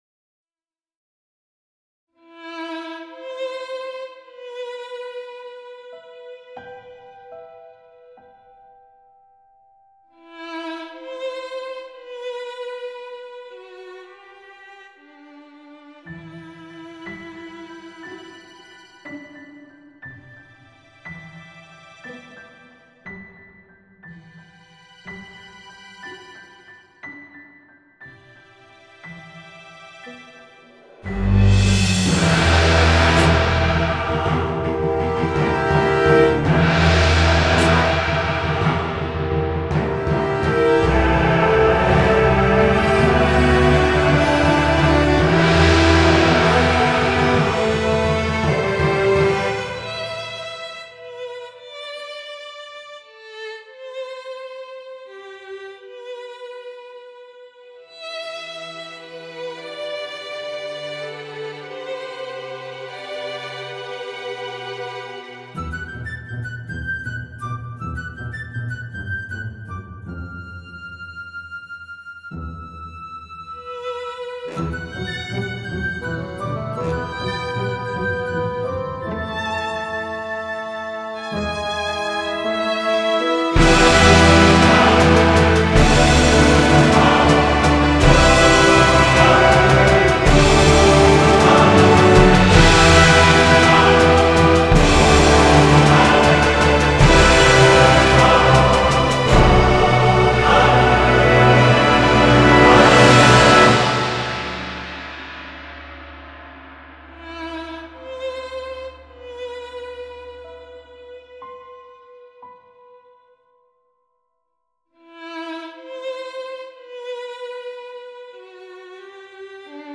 jugez plutôt directement avec ce lien la version East West Quantum Leap Symphonic Orchestra Gold (j'ai choisi celle avec les strings)